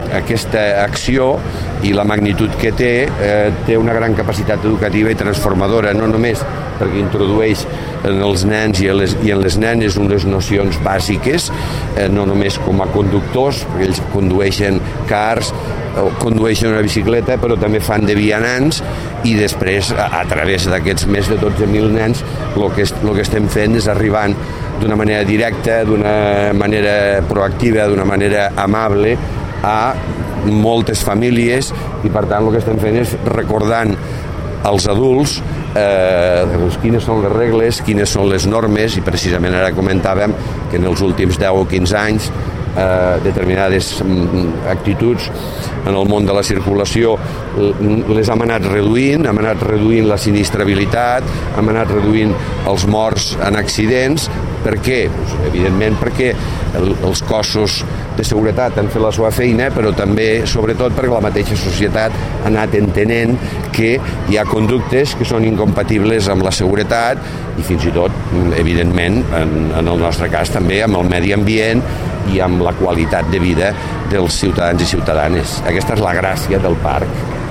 tall-de-veu-de-miquel-pueyo-sobre-el-parc-infantil-de-transit-de-la-paeria